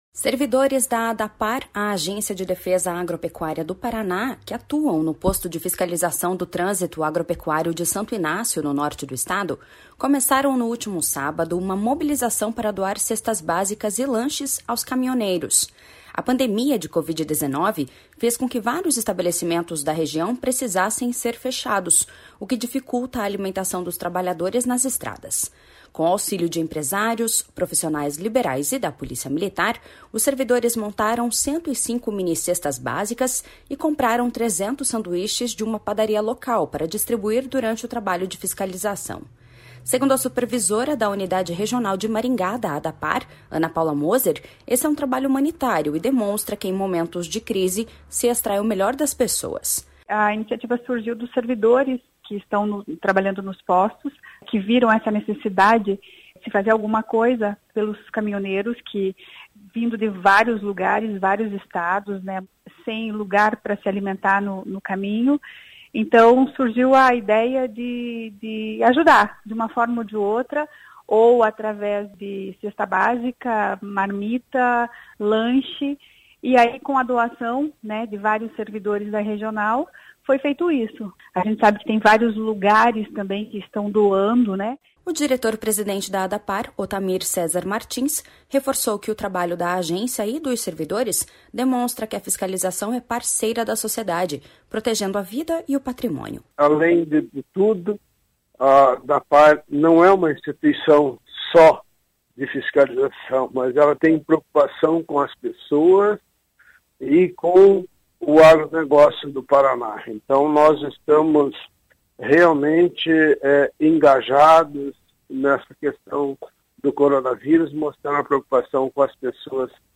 O diretor-presidente da Adapar, Otamir Cesar Martins, reforçou que o trabalho da Agência e dos servidores demonstra que a fiscalização é parceira da sociedade, protegendo a vida e o patrimônio.// SONORA OTAMIR CESAR MARTINS.//